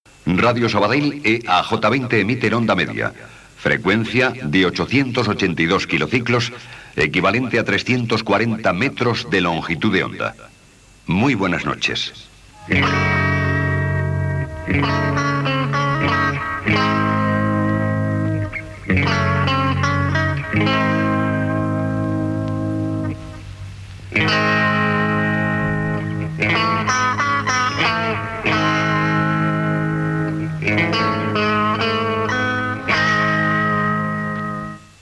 Identificació, freqüència i tancament de l'emissió